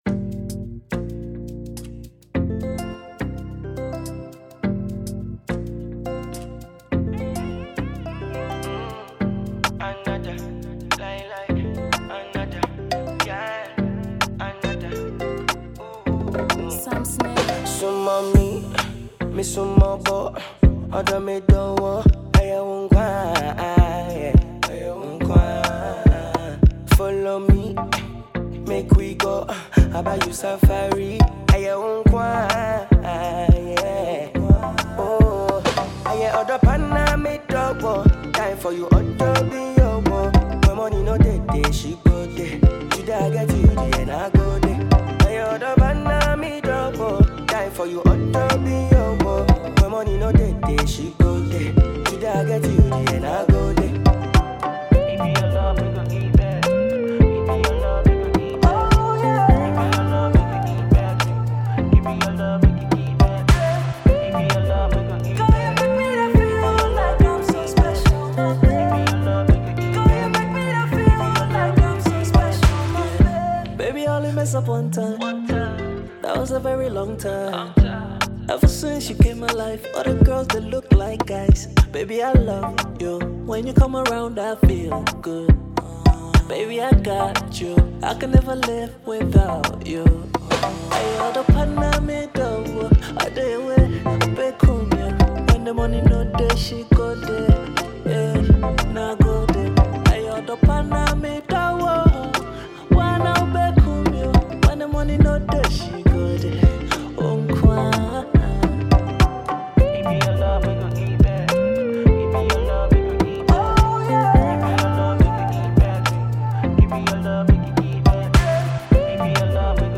With sharp verses and heartfelt delivery
unapologetically authentic and musically rich.